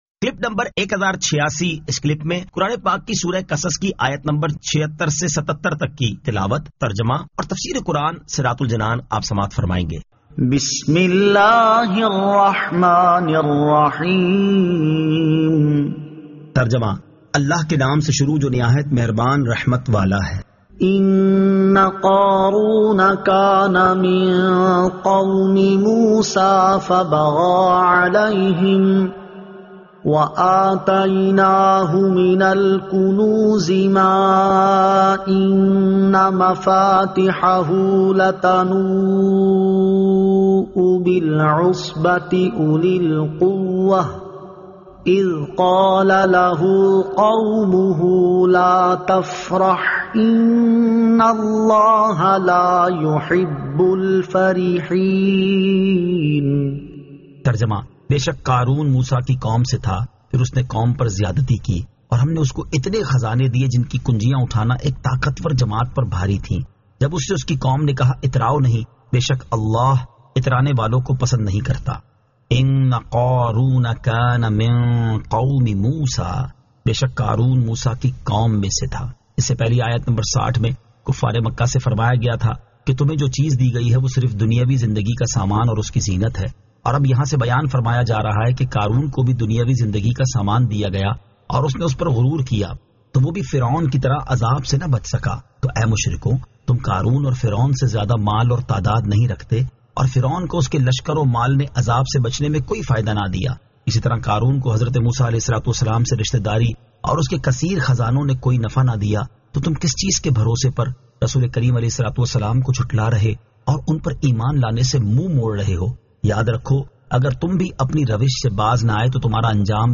Surah Al-Qasas 76 To 77 Tilawat , Tarjama , Tafseer